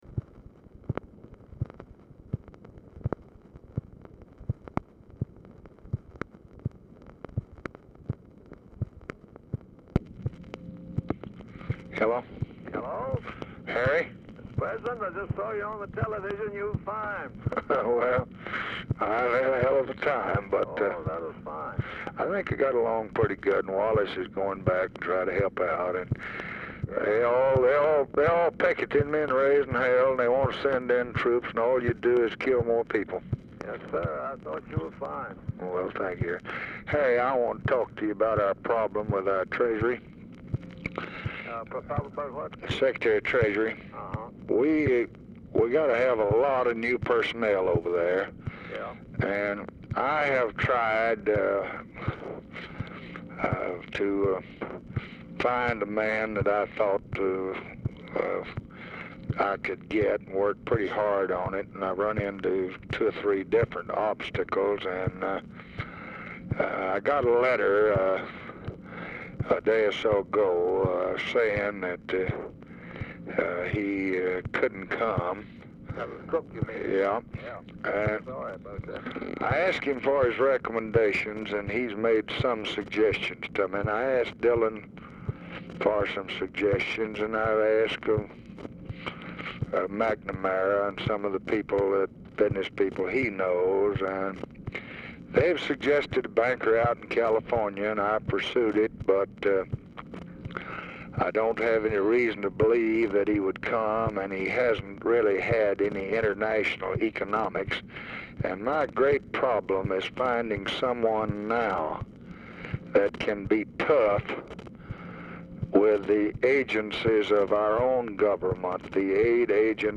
Telephone conversation # 7066, sound recording, LBJ and HARRY BYRD, SR., 3/13/1965, 4:41PM | Discover LBJ
RECORDING OF CONVERSATION IS BRIEFLY INTERRUPTED IN THE MIDDLE
Format Dictation belt
Location Of Speaker 1 Oval Office or unknown location